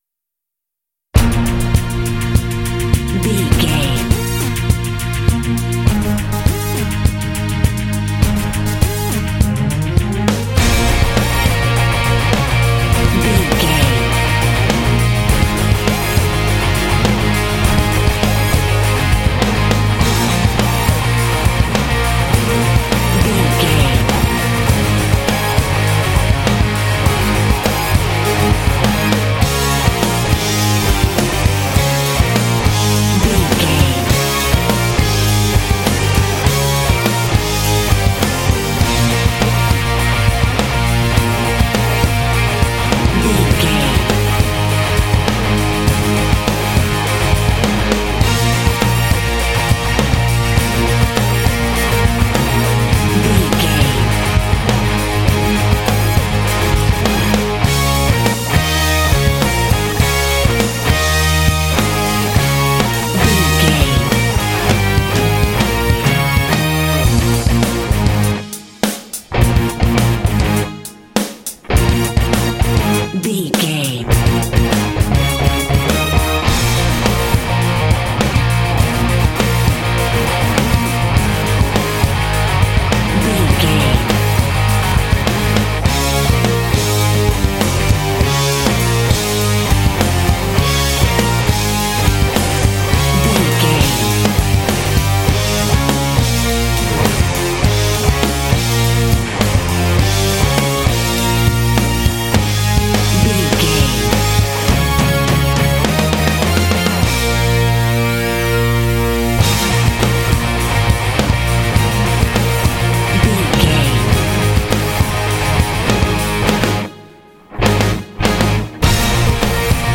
Epic / Action
Aeolian/Minor
B♭
powerful
energetic
heavy
synthesiser
strings
bass guitar
electric guitar
drums
heavy metal
symphonic rock